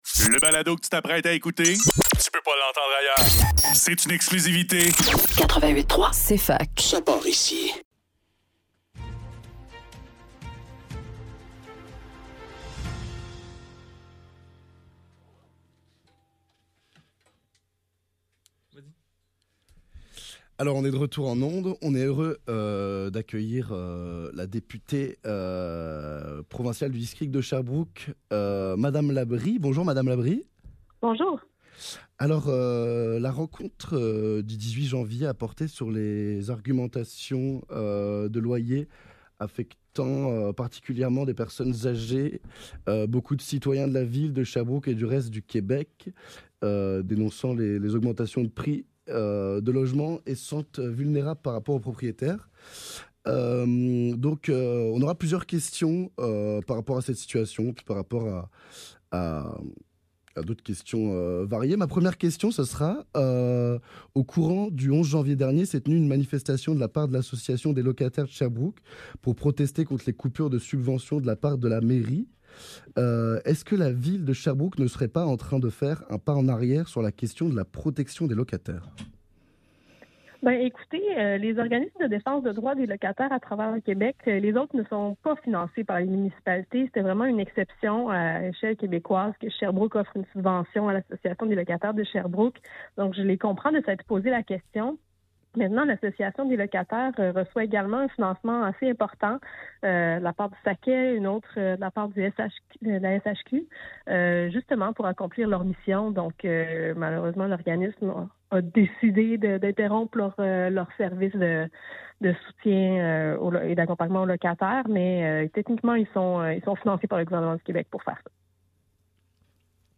Le NEUF - Entrevue avec Christine Labrie - 23 janvier 2024